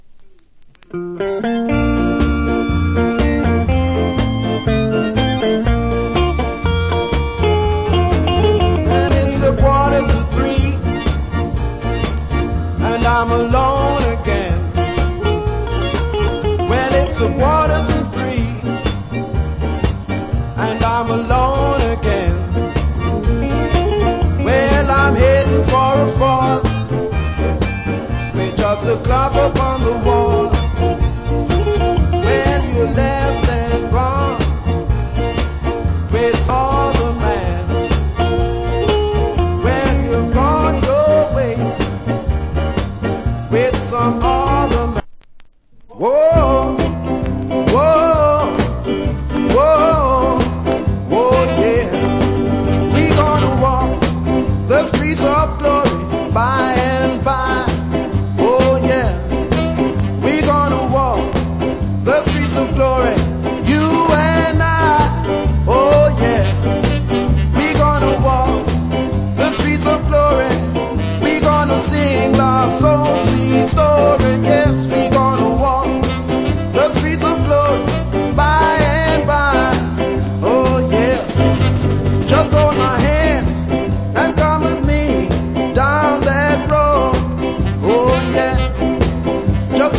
Wicked Ska Vocal. UK Production.